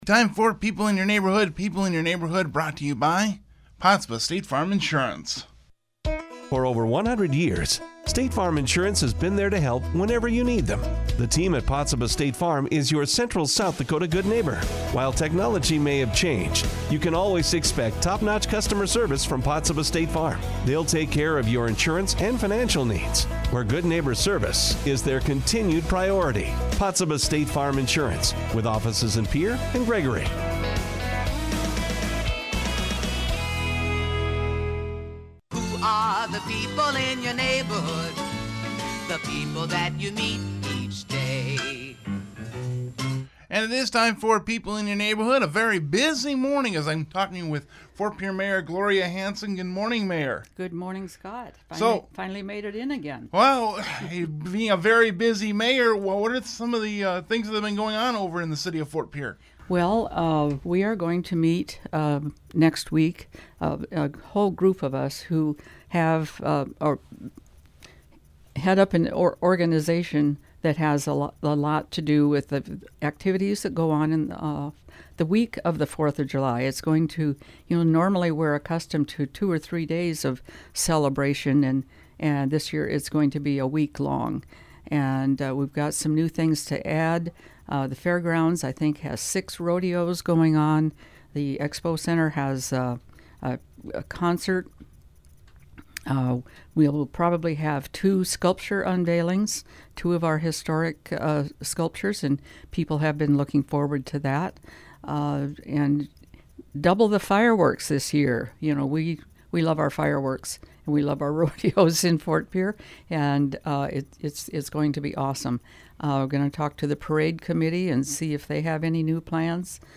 Ft. Pierre mayor Gloria Hanson joined KGFX’s People in Your Neighborhood to talk about happenings in the city.